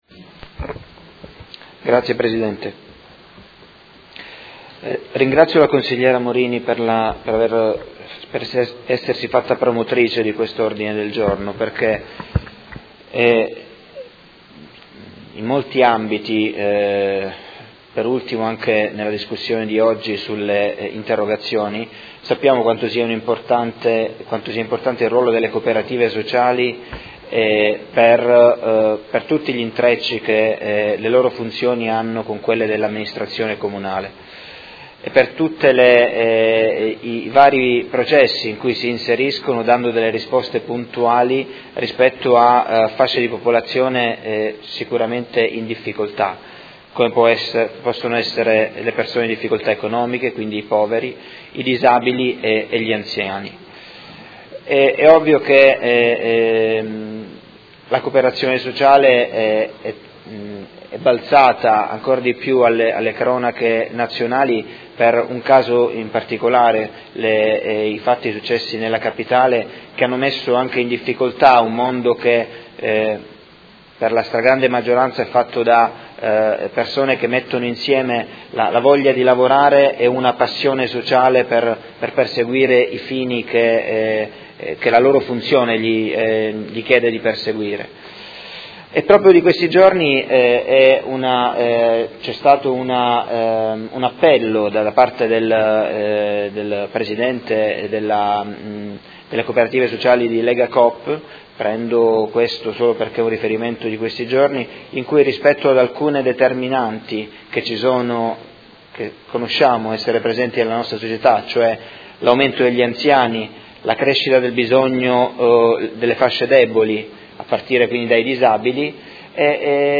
Seduta del 6 ottobre. Ordine del Giorno presentato dai Consiglieri Morini, Venturelli, Forghieri, Fasano, Pacchioni, Stella, Di Paova, Malferrari, Poggi, Lenzini e Trande (P.D.) avente per oggetto: La Cooperazione sociale, modello imprenditoriale che garantisce coesione nella Città.